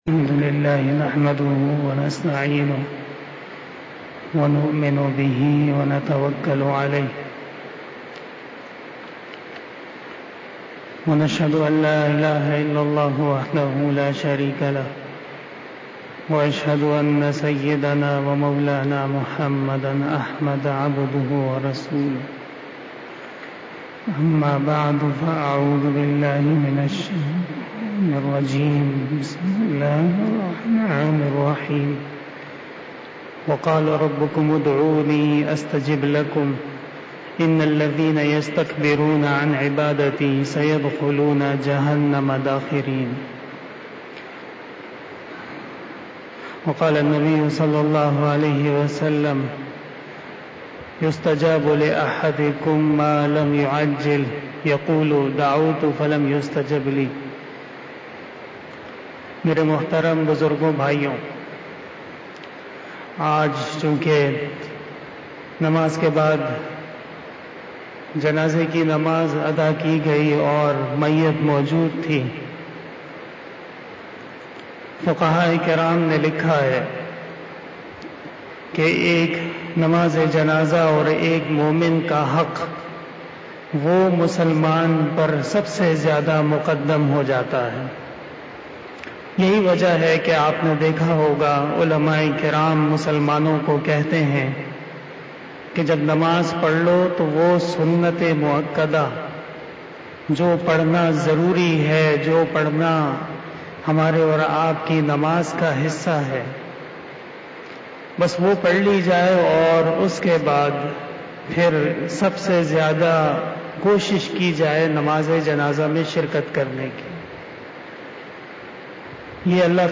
بیان شب جمعۃ المبارک ۱۱ ذی القعدہ ۱۴۴۱ھ بمطابق۰۲ جولائی ۲۰۲۰ء